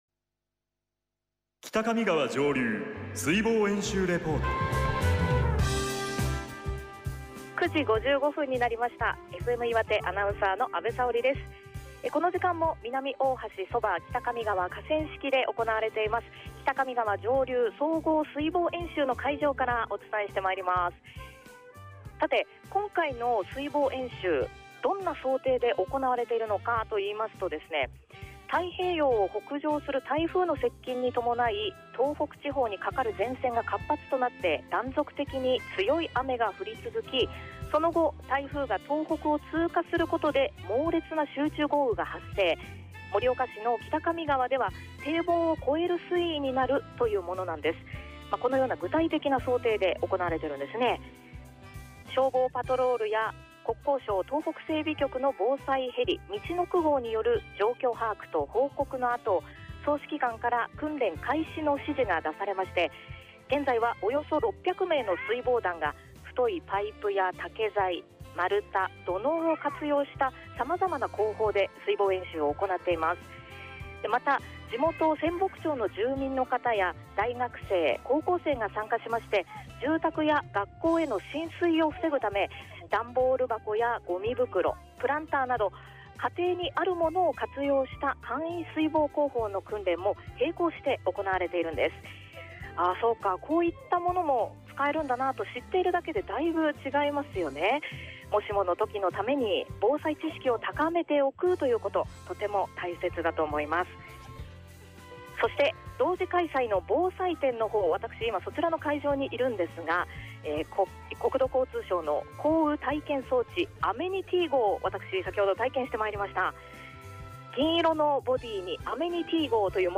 ２０１５北上川上流総合水防演習　実況レポート　【提供：潟Gフエム岩手】
A５月２４日（日）08：55　レポート